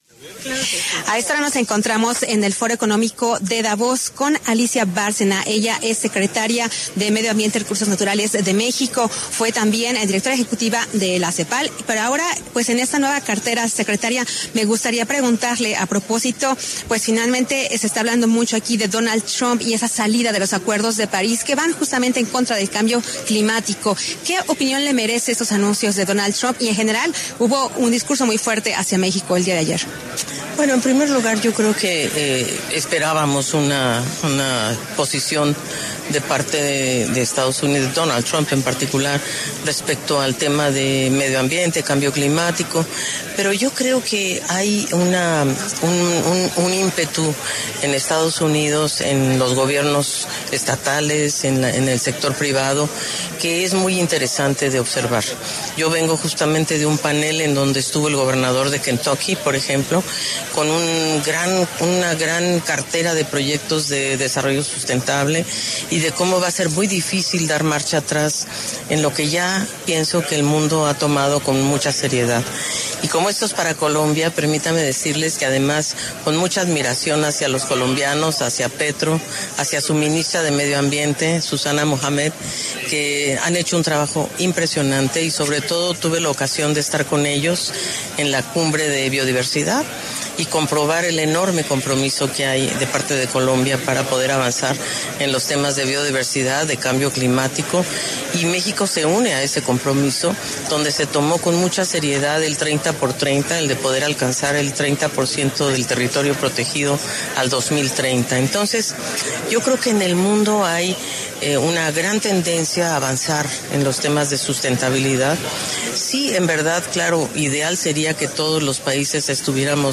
En el marco del Foro de Davos, Alicia Bárcena, secretaria de Medio Ambiente y Recursos Naturales de México, pasó por los micrófonos de La W y entregó detalles sobre los anuncios energéticos que hizo Donald Trump en sus primeras horas de segundo mandato.